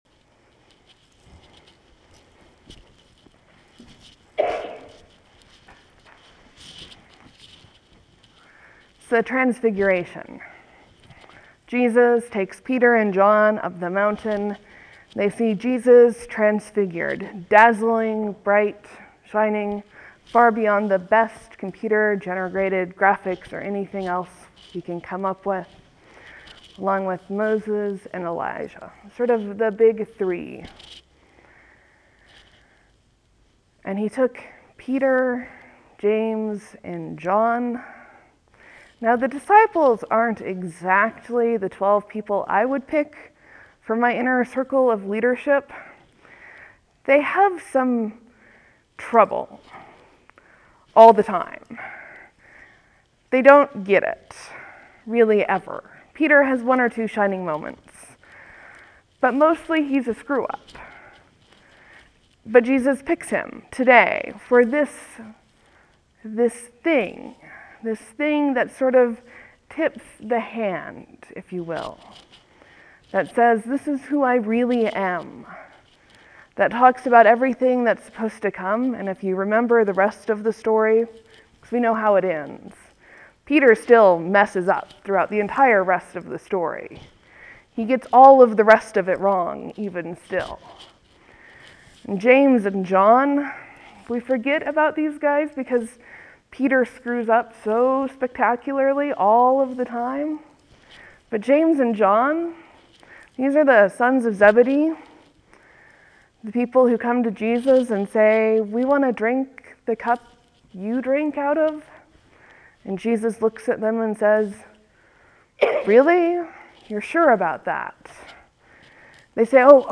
The flawed inner circle: a sermon for the last Sunday after Epiphany 2-10-13
(There will be a few moments of silence before the sermon begins. Thank you for your patience.)